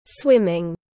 Môn bơi lội tiếng anh gọi là swimming, phiên âm tiếng anh đọc là /ˈswɪmɪŋ/
Swimming /ˈswɪmɪŋ/